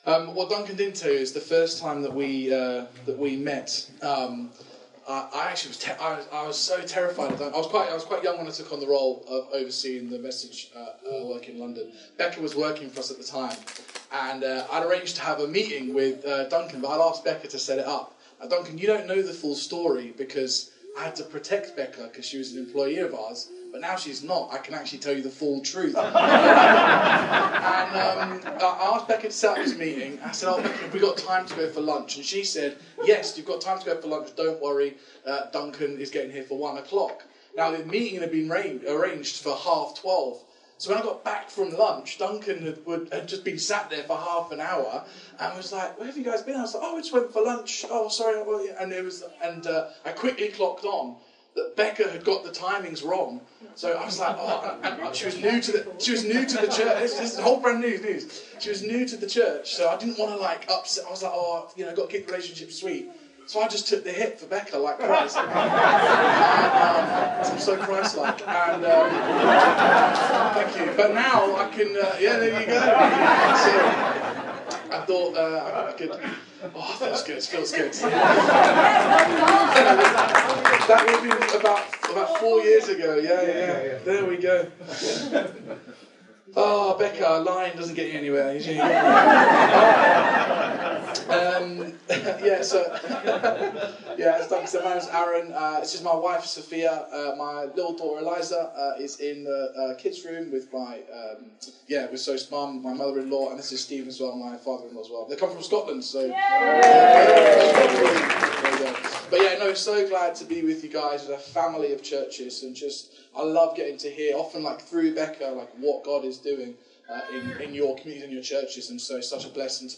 Download Sent To The Marginalised | Sermons at Trinity Church